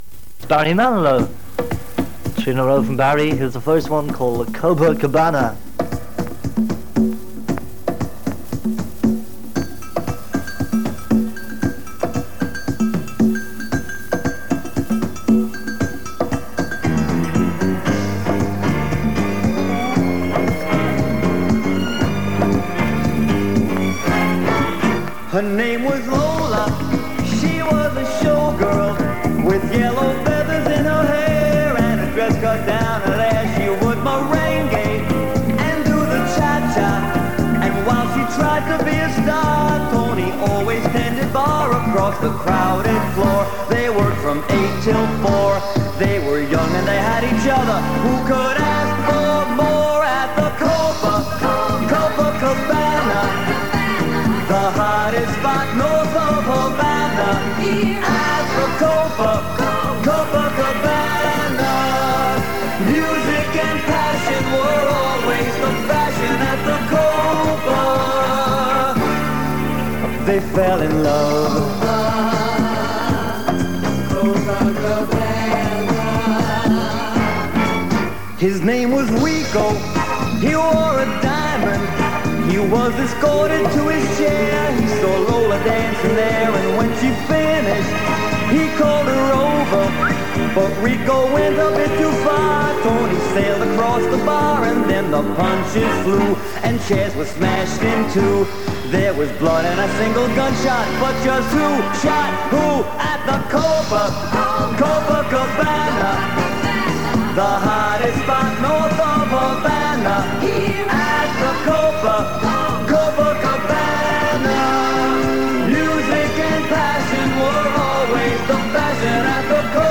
This recording contains excerpts of shows by various DJs on Cork pirate Radio City in early summer 1981.
The bulk of the recording was made from 95.5 FM from around 1700 on Saturday 2nd May 1981 but contains a number of edits.
Audio quality is fair with some cassette wobble and distortion.